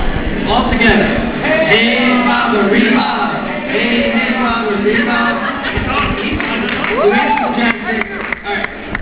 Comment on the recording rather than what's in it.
on-stage with the audience!!